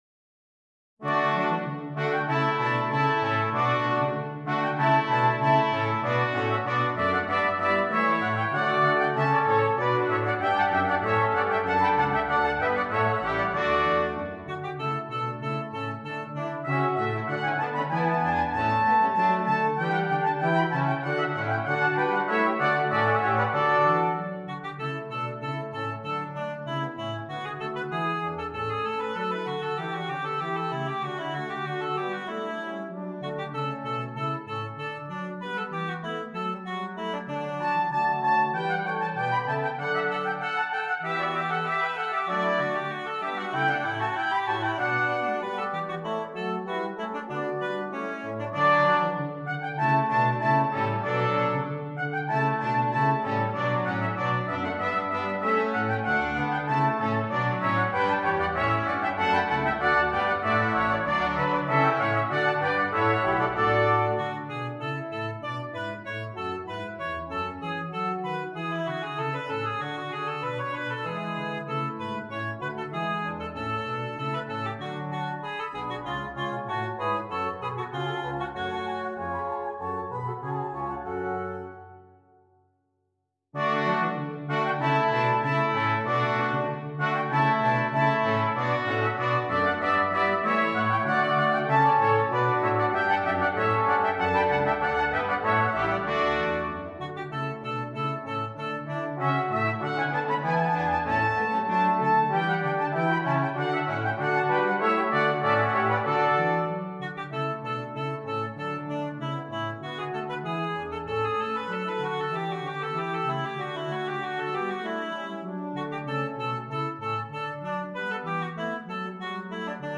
Brass Quintet and Soprano